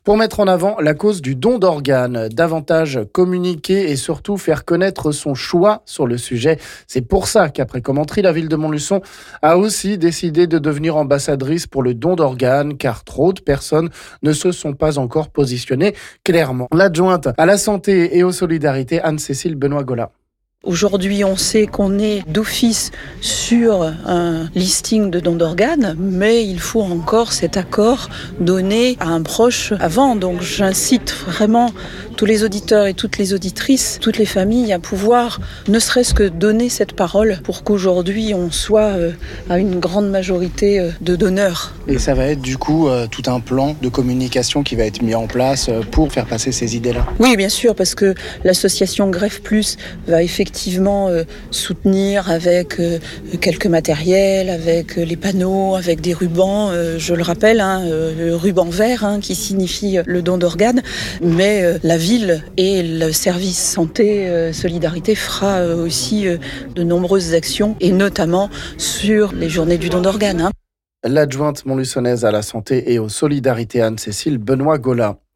L'adjointe à la santé et aux solidarités Anne-Cécile Benoît-Gola nous en parle...